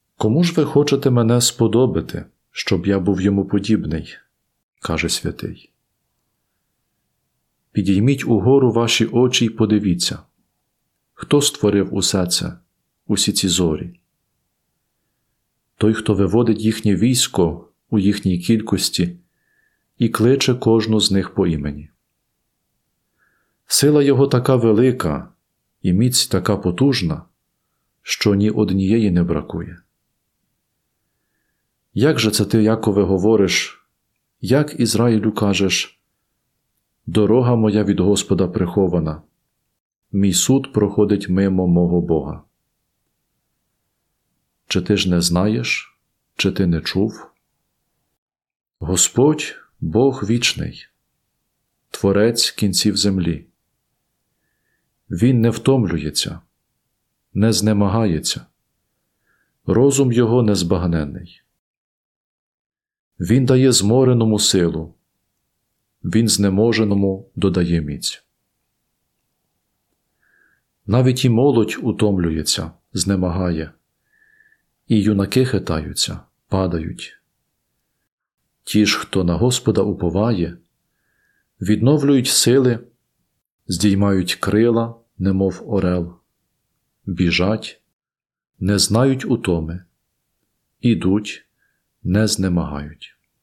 Перше читання